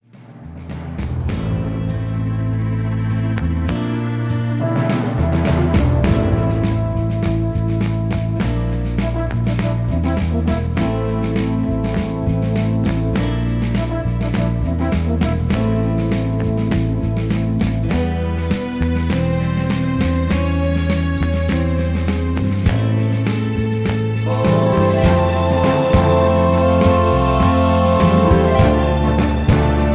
karaoke , sound tracks , backing tracks